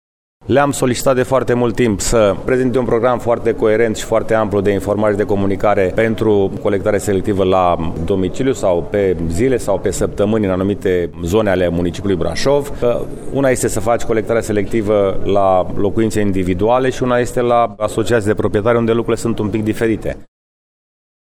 Primarul Braşovului, George Scripcaru, sustine că le-a cerut operatorilor de salubritate să pornească o campanie de informare privind colectarea selectivă: